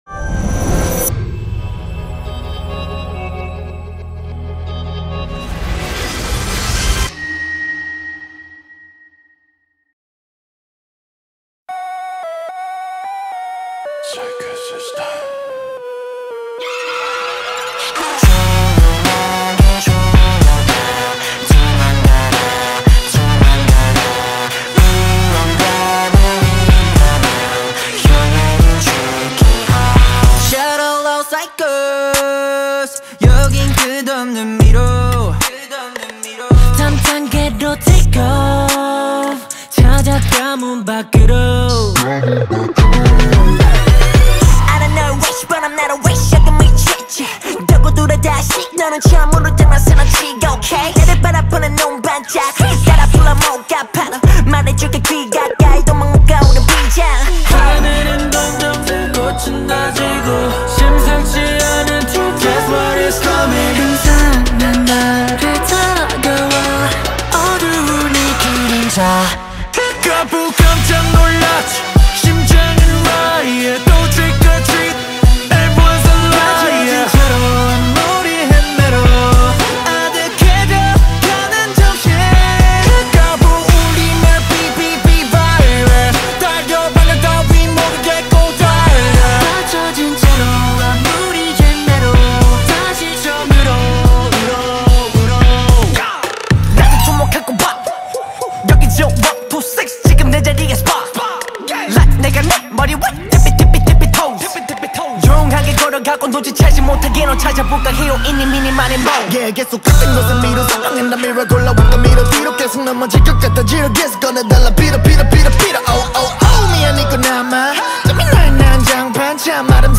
Азиатские хиты